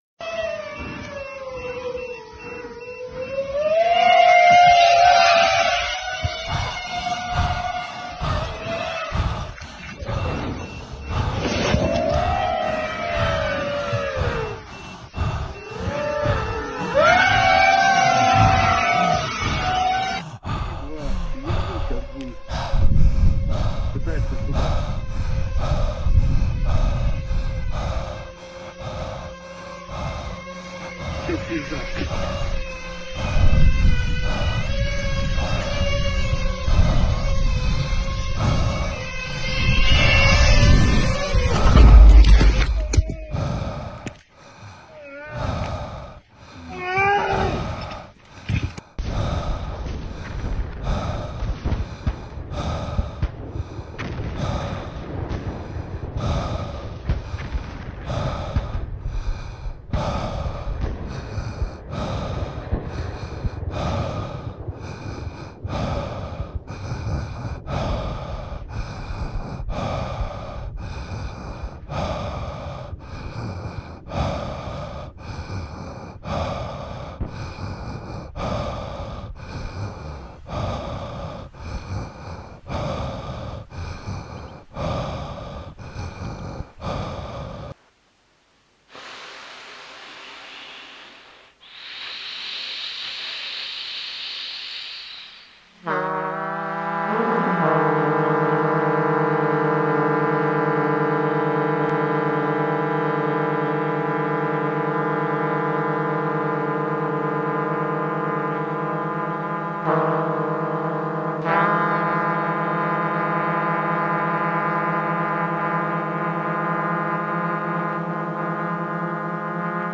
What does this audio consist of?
trompette, voix.